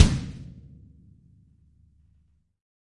描述：using a Behringer ECM8000, a practice run
on a partially homecreated drum kit
drumkit drums
loud percussion
声道立体声